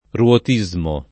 ruotismo [ r U ot &@ mo ] → rotismo